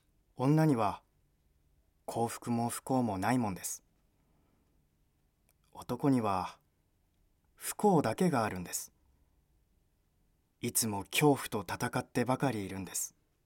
ボイスサンプル
セリフB